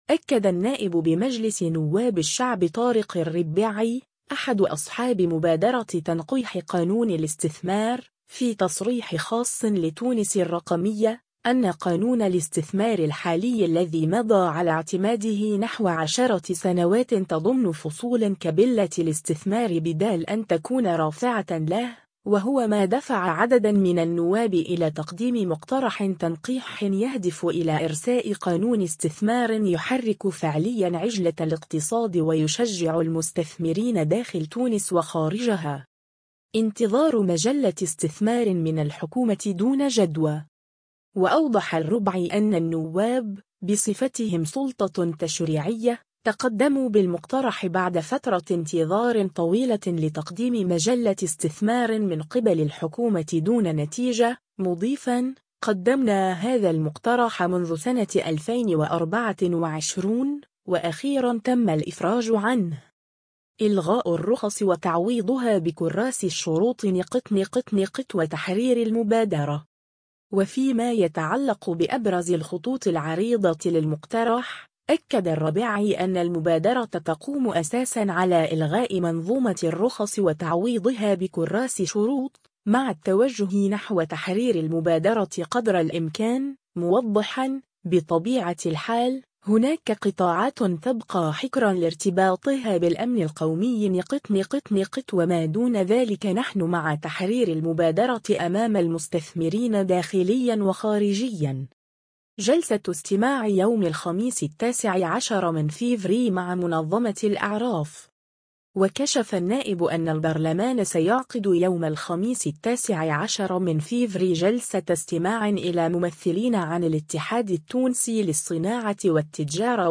أكد النائب بمجلس نواب الشعب طارق الربعي، أحد أصحاب مبادرة تنقيح قانون الاستثمار، في تصريح خاص لـ“تونس الرقمية”، أن قانون الاستثمار الحالي الذي مضى على اعتماده نحو 10 سنوات تضمّن فصولًا “كبّلت الاستثمار بدل أن تكون رافعة له”، وهو ما دفع عددًا من النواب إلى تقديم مقترح تنقيح يهدف إلى إرساء قانون استثمار “يحرّك فعليًا عجلة الاقتصاد” ويشجّع المستثمرين داخل تونس وخارجها.